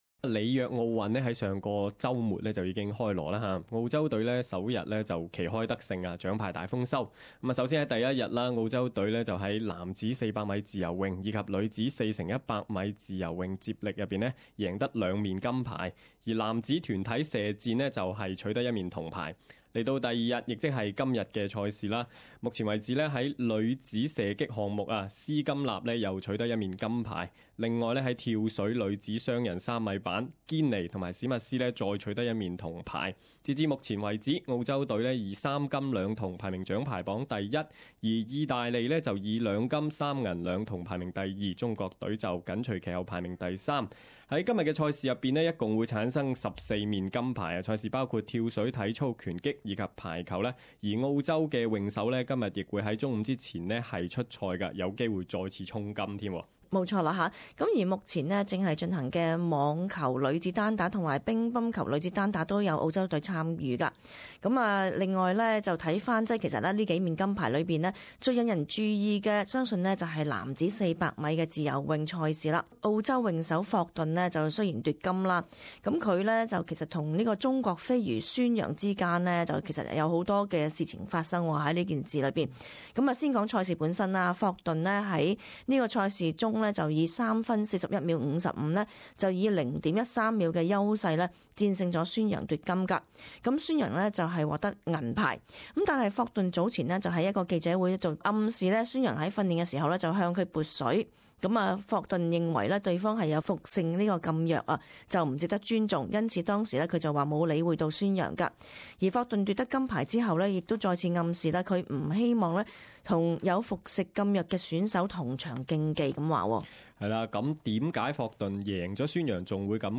【時事報導】霍頓指孫楊服用禁藥不值得尊重